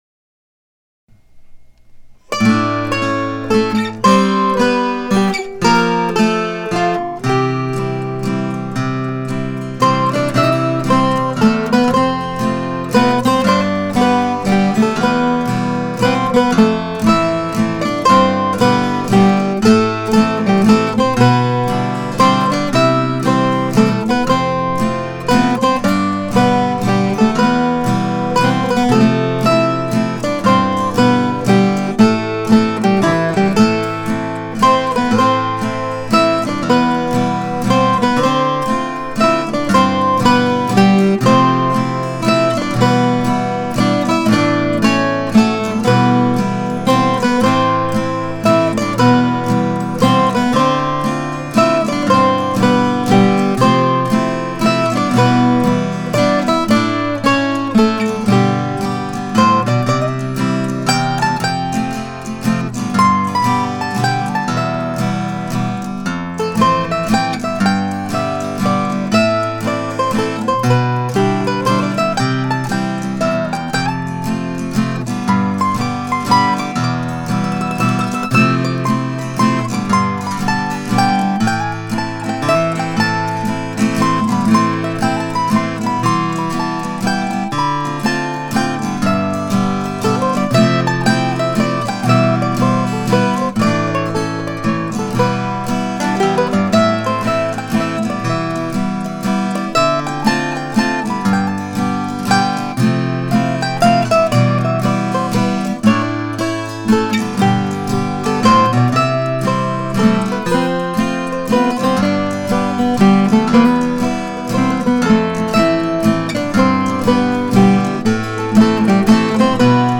Back in November, before Thanksgiving, I found this very simple waltz waiting for me.